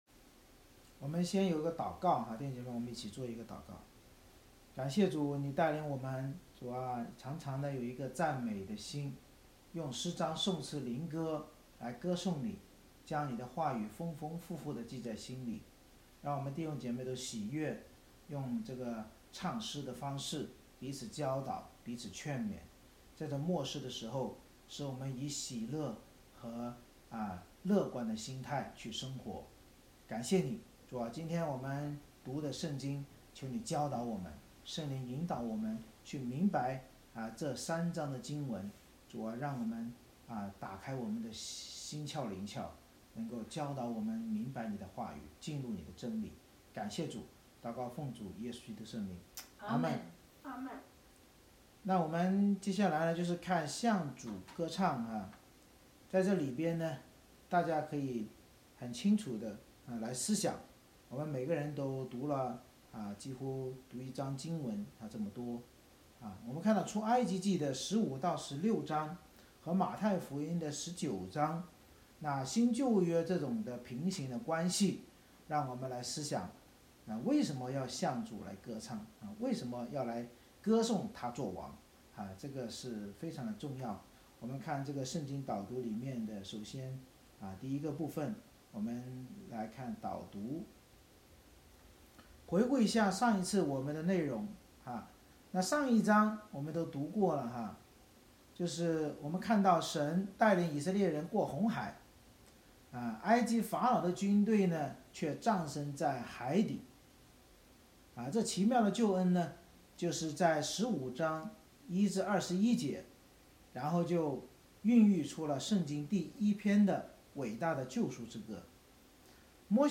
每次崇拜以经文诗歌赞美开始，然后会众以接力方式读3-4章经文（中/英文）或角色扮演，并简单分享，最后由牧师藉着新书《圣经导读新唱365》的导读和新歌进行释经讲道。